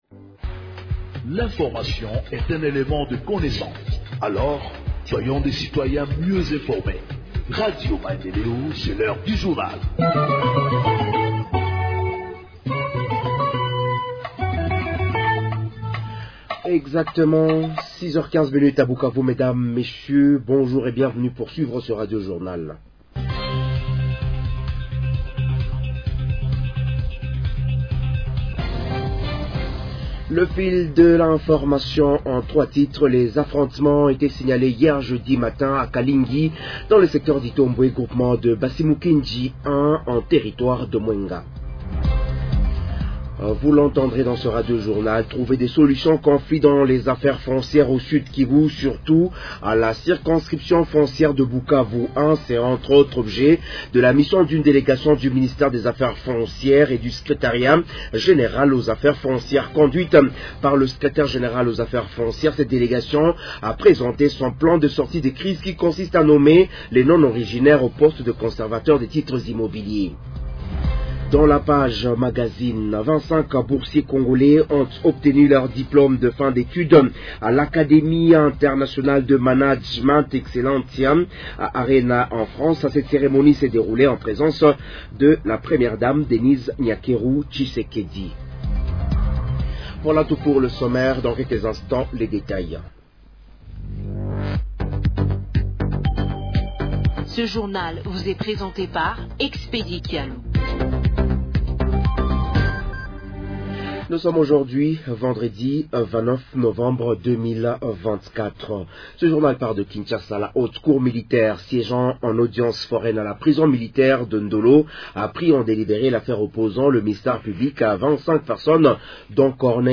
Journal en Français du 29 novembre 2024 – Radio Maendeleo